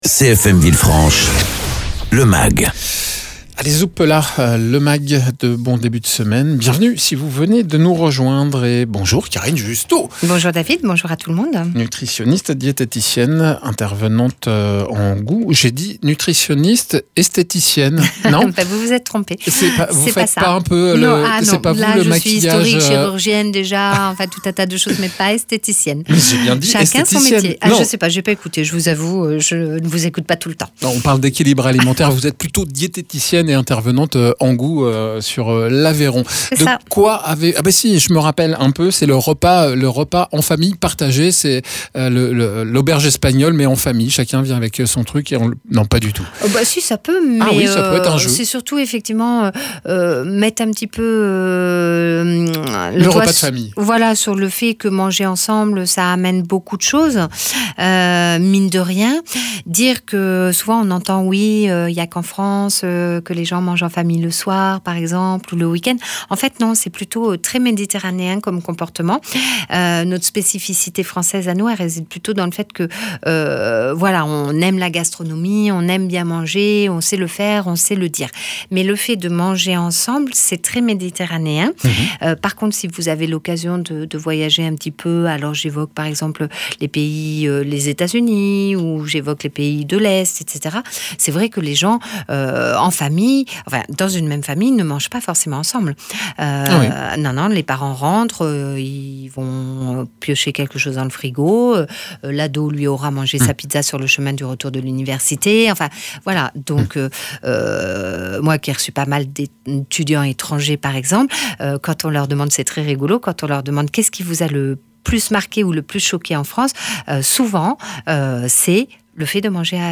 nutritionniste diététicien
naturopathe